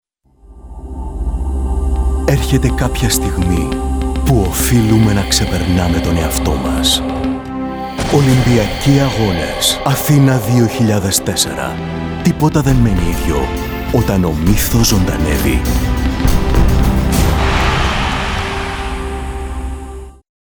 Sonorer Bariton für Sprechertätigkeiten aller Art.
Sprechprobe: Industrie (Muttersprache):